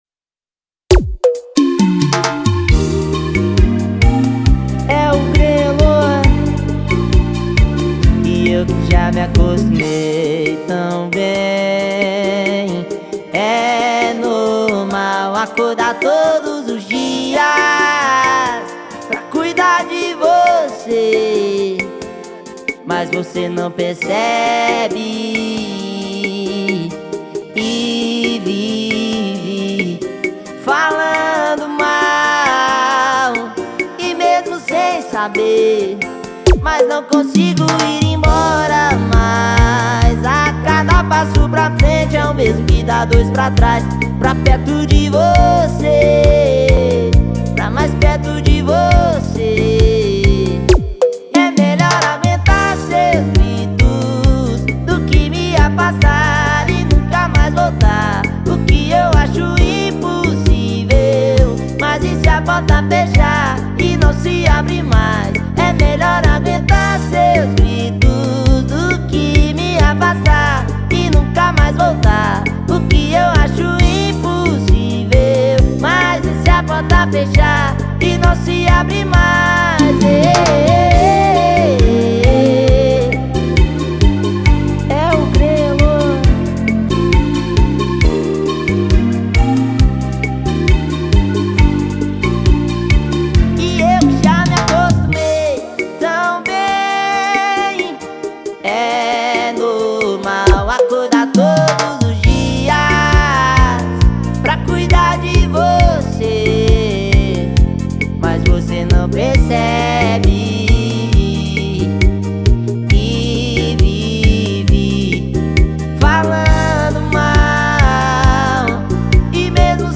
2025-01-23 11:36:38 Gênero: Forró Views